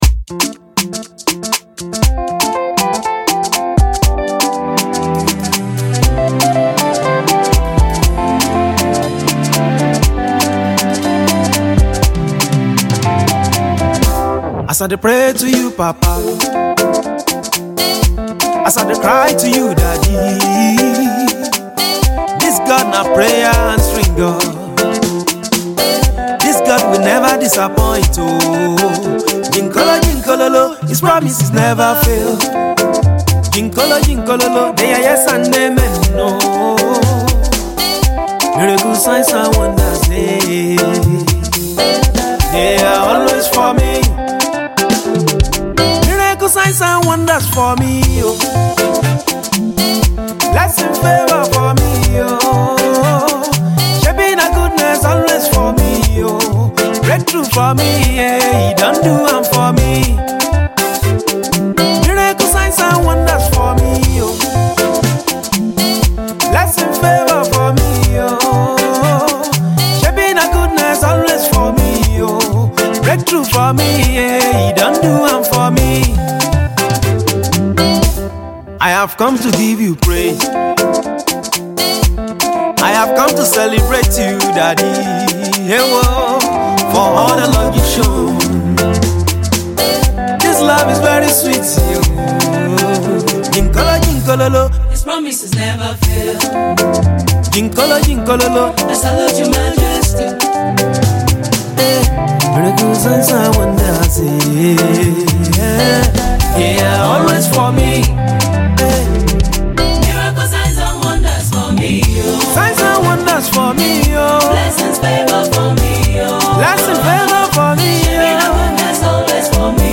Gospel music
Highlife Afro-fusion